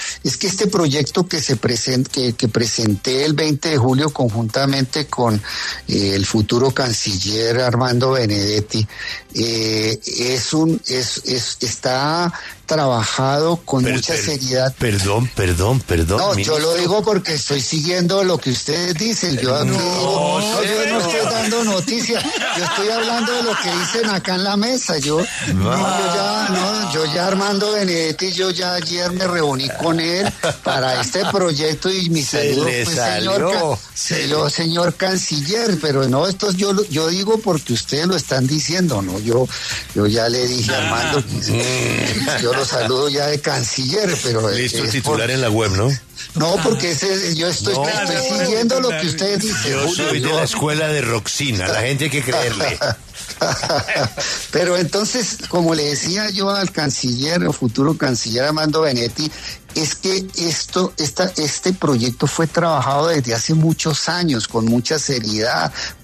En diálogo con La W, el ministro de Justicia, Eduardo Montealegre, llamó a Armando Benedetti “futuro canciller”.